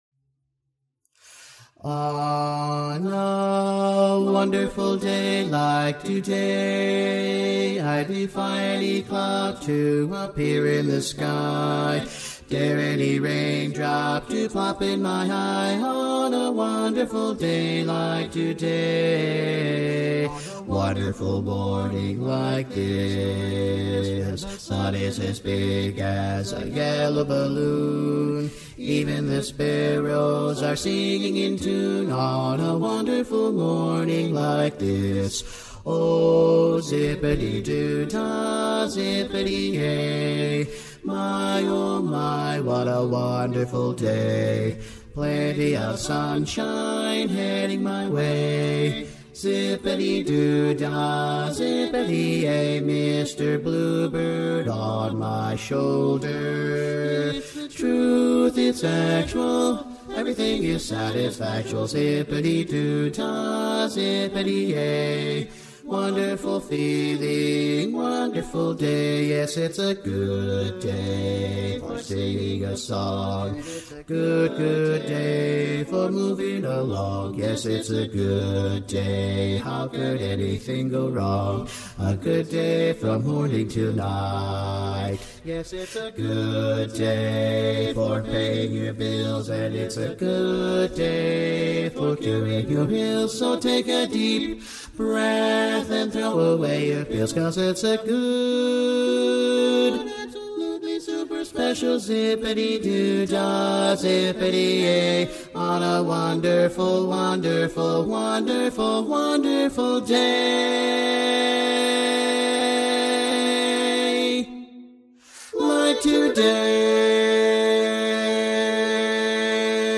Harmony Rechoired (chorus)
Up-tempo
F Major